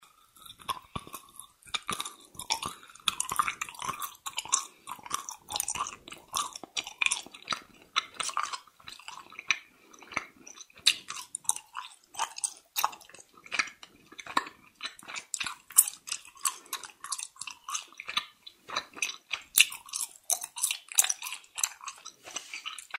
Звуки жвачки
Вкусно жует